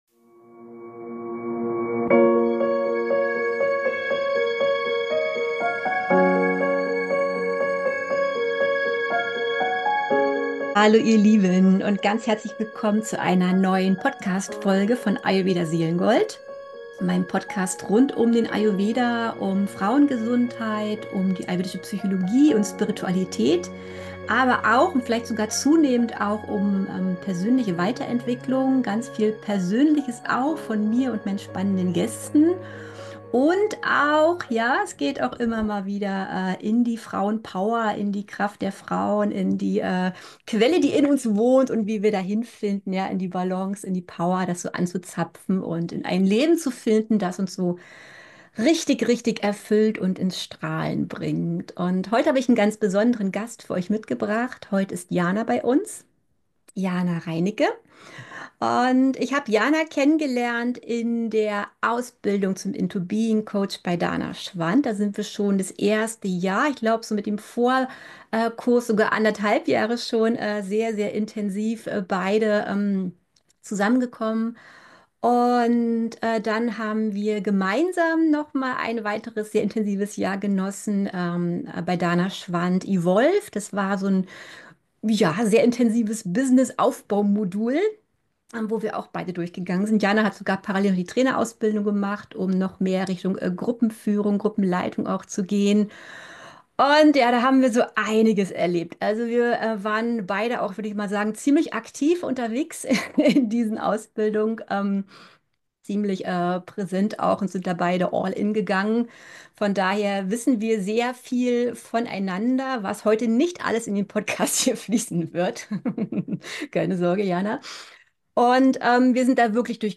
Aus der Härte in die Milde – Die Kunst der weiblichen (Selbst-)Führung – Im Gespräch